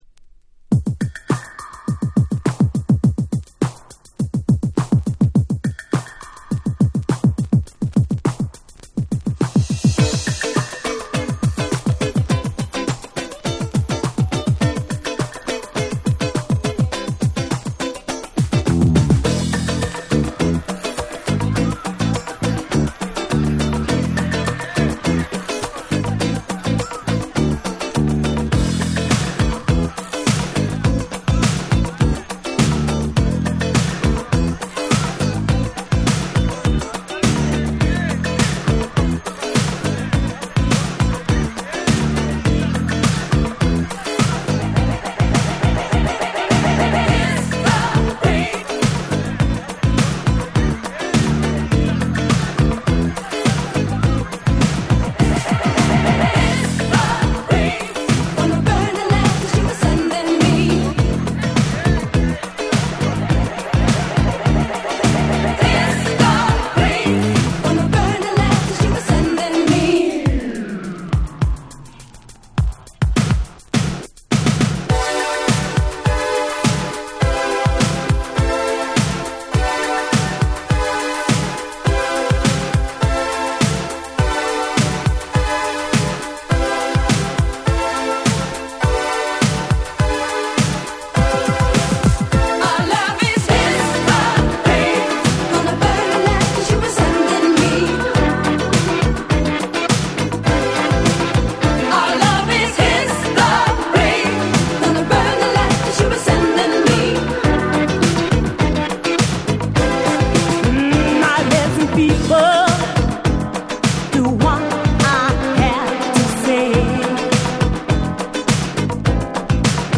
85' Super Hit Disco !!
オランダ産スーパーヒットディスコ！！
Dance Classics Boogie ブギー